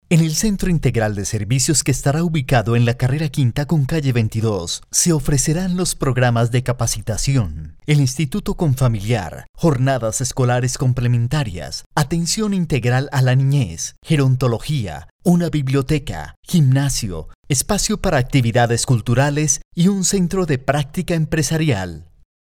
Voz joven, dinamica, también puede ser amable e institucional; puedo producir totalmente su spot comercial o simplemente grabar la voz en off
Sprechprobe: eLearning (Muttersprache):
young voice, dynamic, can also be kind and institutional I can fully produce your commercial spot or simply record the voiceover